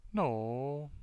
Possible phrase-final tonal configurations in American English